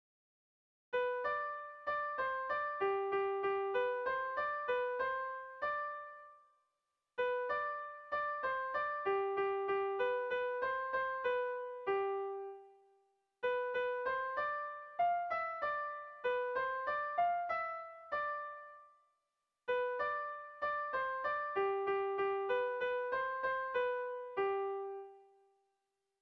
Irrizkoa
Zortziko txikia (hg) / Lau puntuko txikia (ip)
A1A2BA2